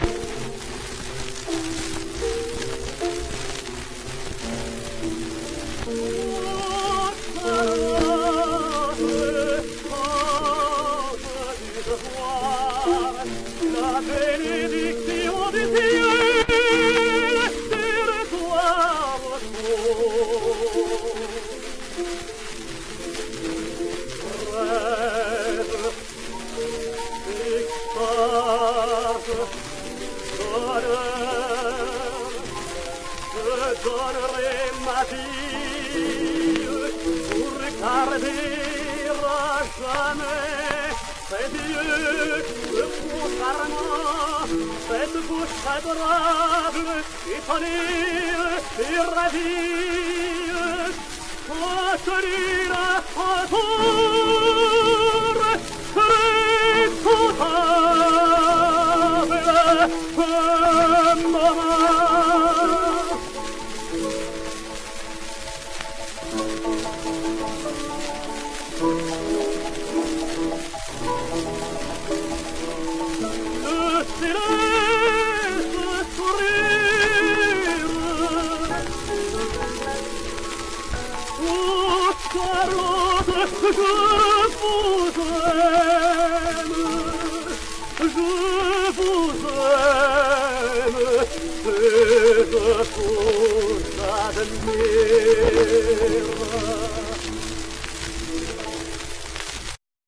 Charles Rousselière singsWerther: